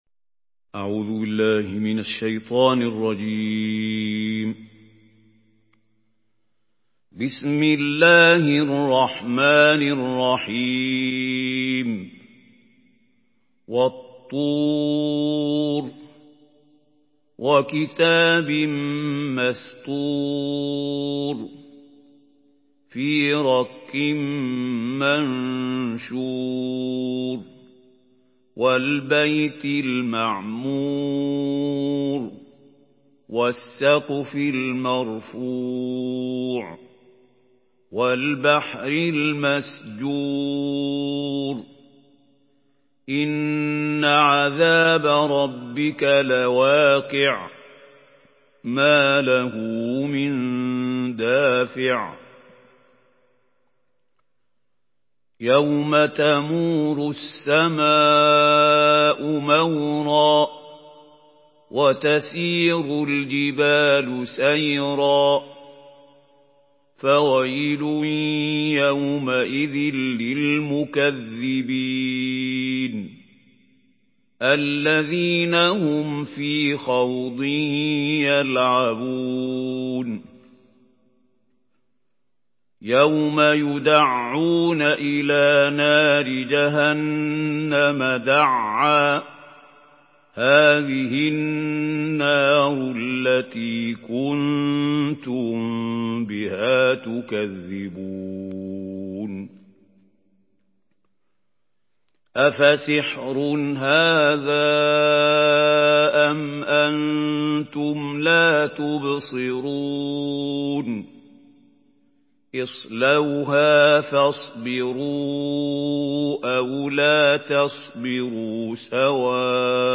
Écoutez la Sourate At-Tur à la Voix de Mahmoud Khalil Al-Hussary en ligne, avec la possibilité de télécharger directement au format MP3 avec plusieurs qualités. Une récitation touchante et belle des versets coraniques par la narration Hafs An Asim.
Murattal Hafs An Asim